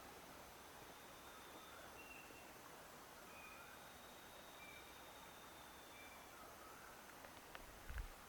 Atajacaminos Oscuro (Antrostomus sericocaudatus)
Nombre en inglés: Silky-tailed Nightjar
Localidad o área protegida: Pe da Serra do Tabuleiro--estrada Pilões
Condición: Silvestre
Certeza: Vocalización Grabada
Atajacaminos-Oscuro.mp3